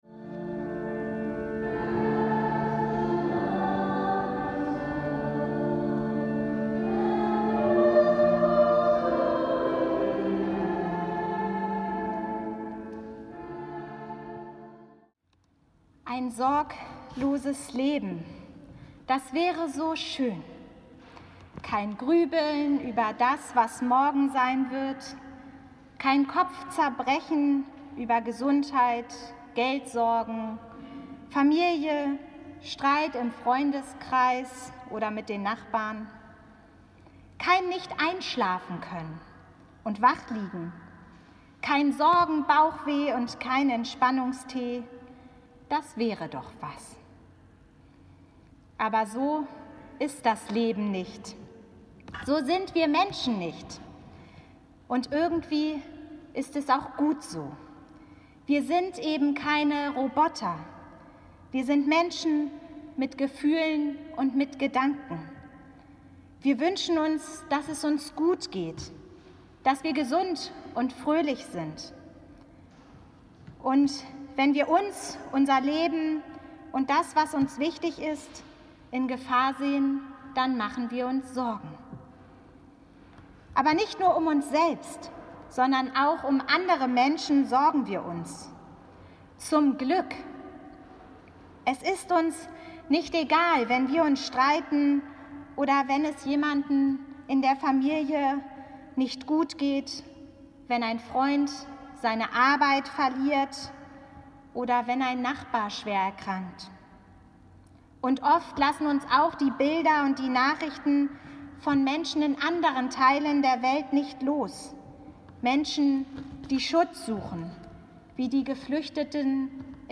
Gottesdienst am Sonntag 20.9.2020
Die biblischen Texte, die Predigt und die Gebete drehen sich um das Thema „Sorgt euch nicht!“. Die Predigt zum Nachhören finden Sie hier (Link).